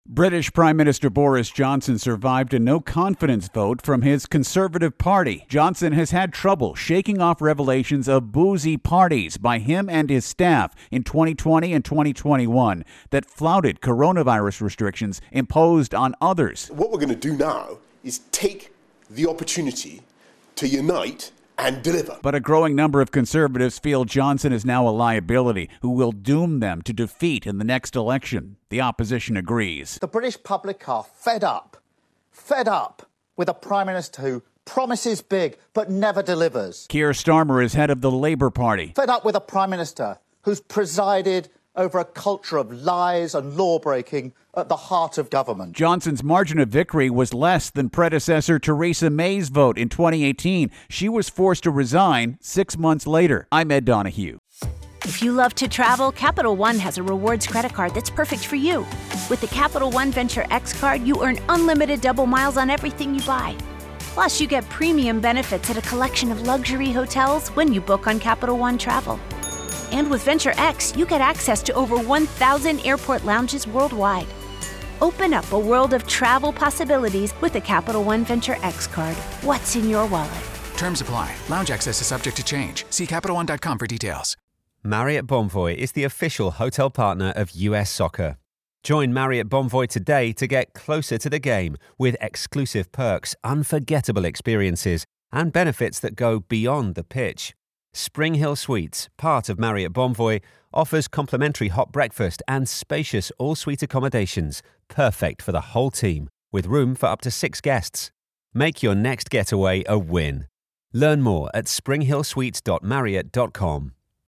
reports on Britain Politics.